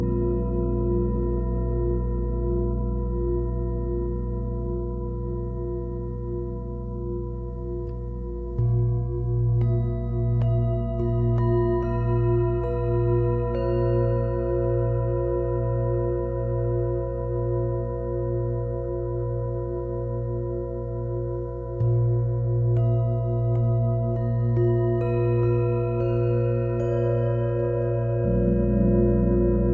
Ausgleichend und harmonisierend